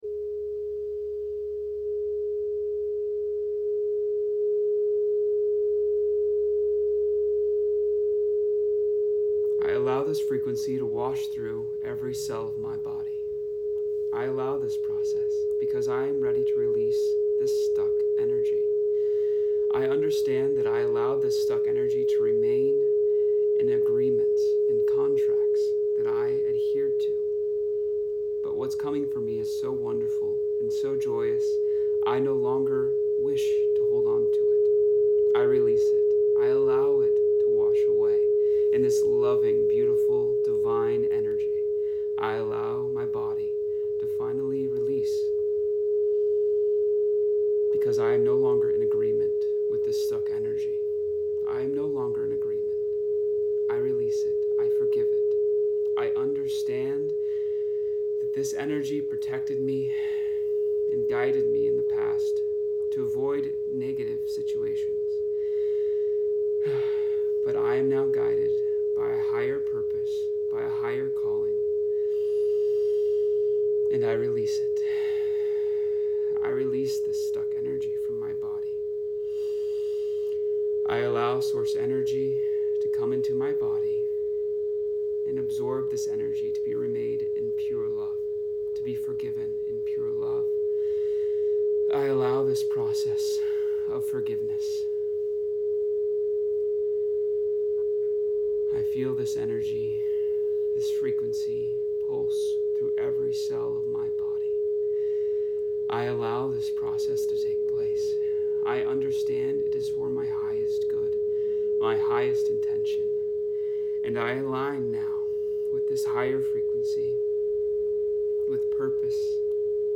417Hz & 528Hz frequency bath with guided meditation to help you become free of the burden. Move forward and allow this energy to flow through every cell.